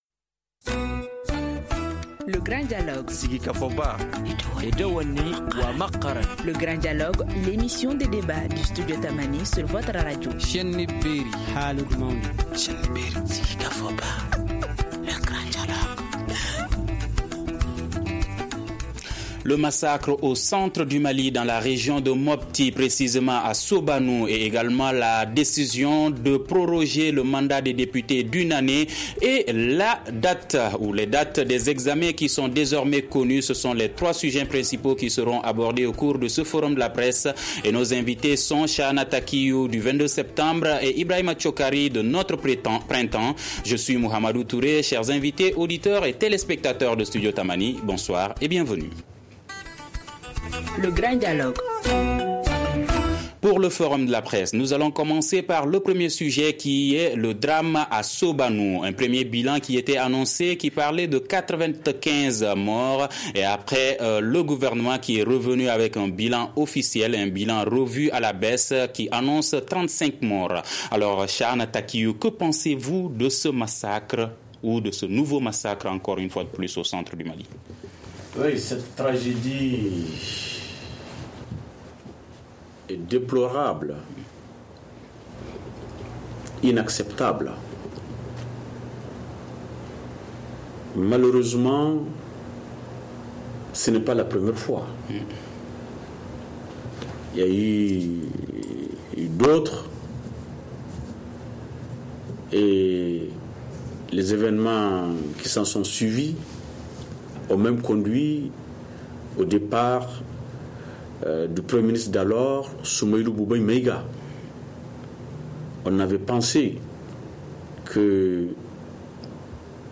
Nos invités : (tous journalistes)